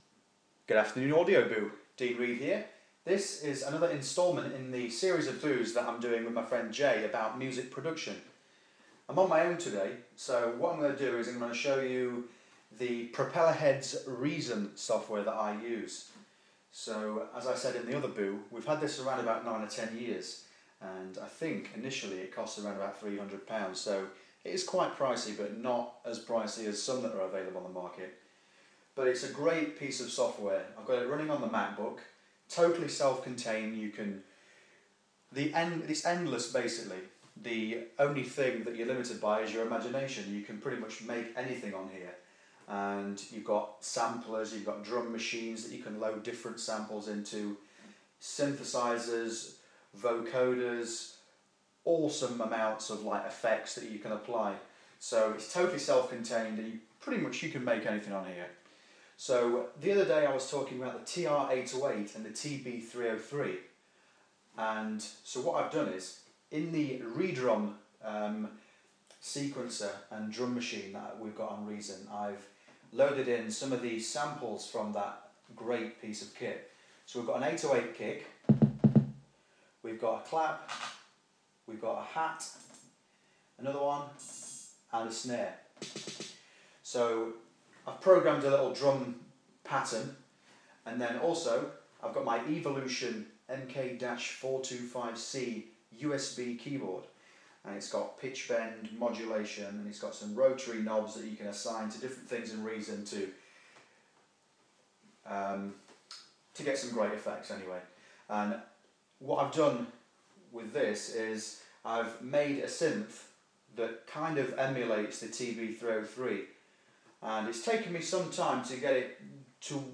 Another installment in a series of Boos about my experience with making music. In this Boo I give you a demo of Propellerheads Reason while playing with TR-808 drum sounds and I patch I've made that emulates the TB-303 Bass Synth.